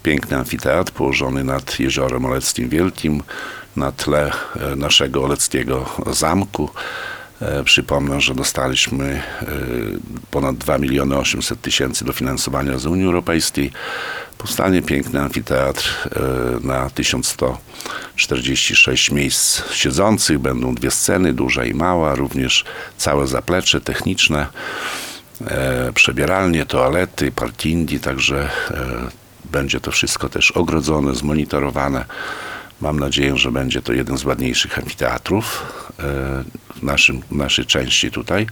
– Będzie 1146 miejsc siedzących – mówił w środę (24.10.18) w Radiu 5 Wacław Olszewski, burmistrz Olecka.